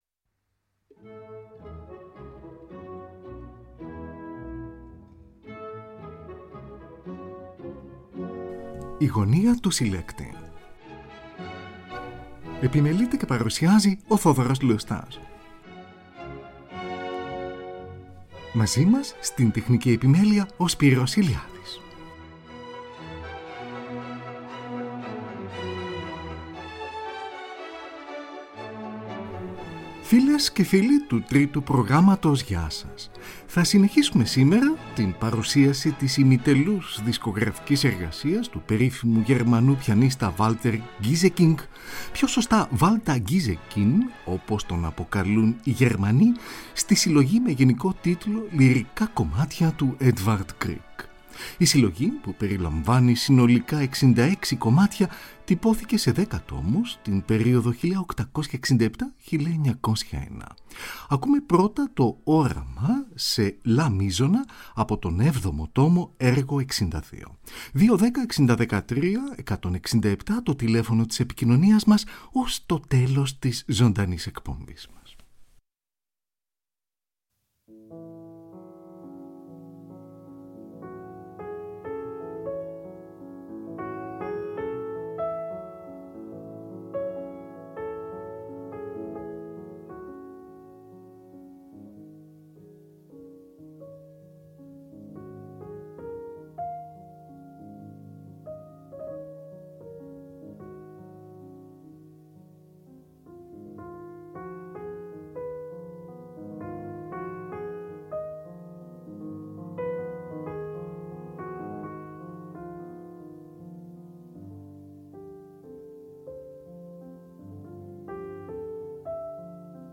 περιλαμβάνει 66 σύντομες συνθέσεις για πιάνο